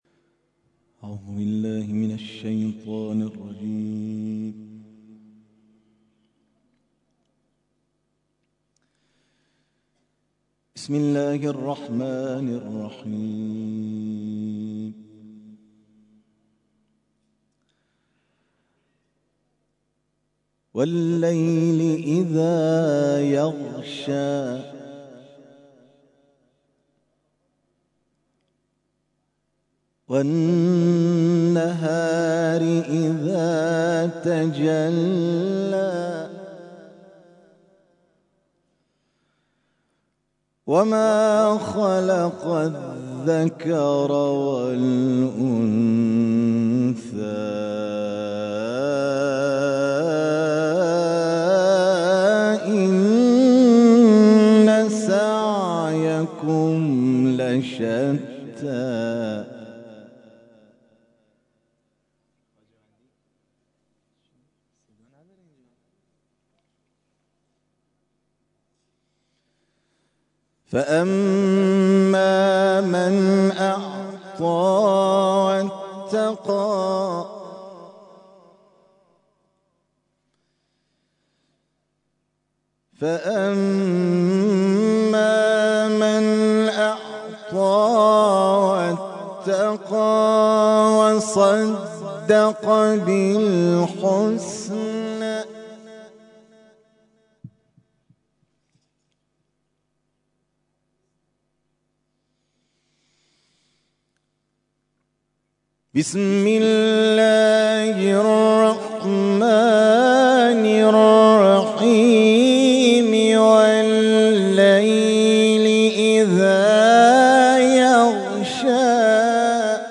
تلاوت مغرب